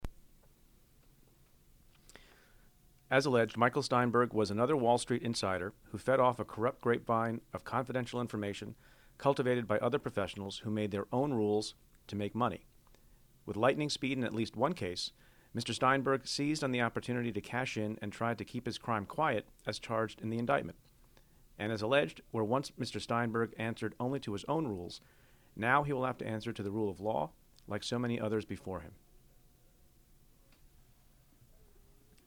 Statement Of Manhattan U.S. Attorney Preet Bharara